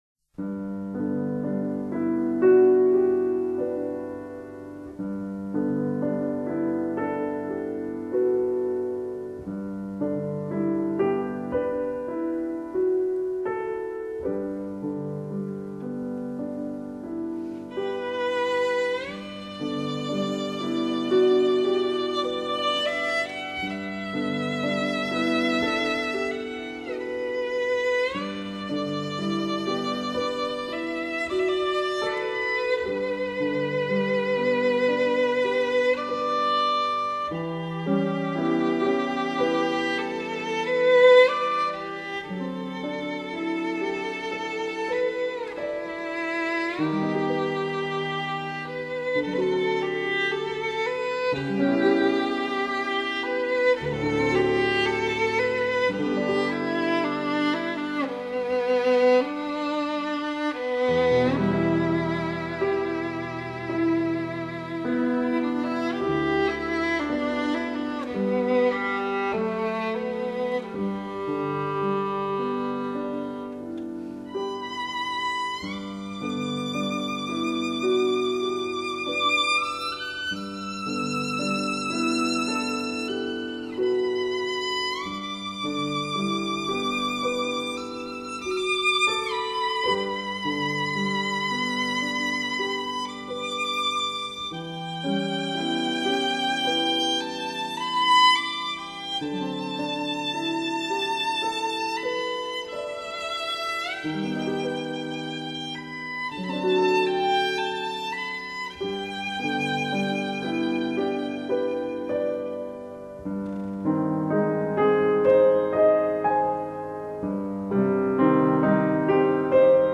小提琴
分类：:发烧/试音